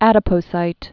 (ădə-pō-sīt)